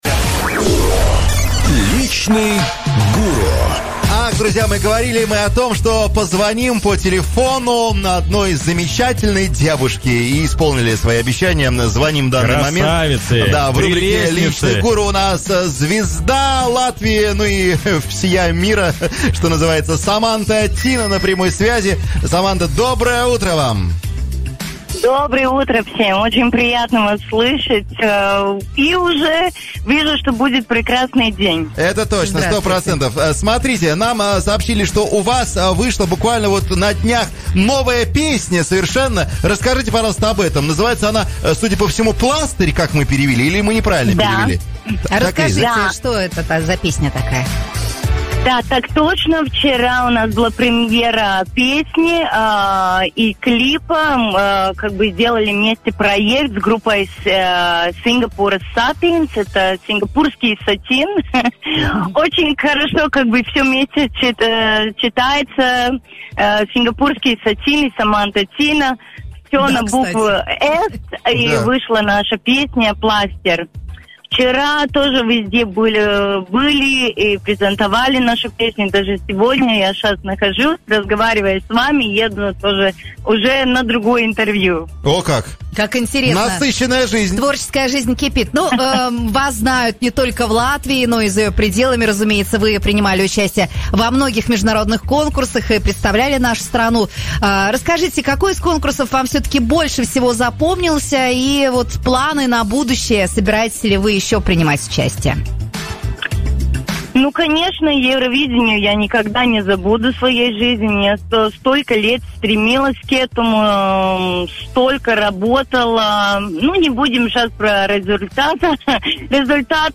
Певица Саманта Тина рассказала «Авторадио» о своей новой песне «Пластырь», исполненной вместе с группой Сингапурский сатин, об участии в Евровидении, идеях для нового имиджа, возможных будущих дуэтах и о том, на кого подписана в Instagram.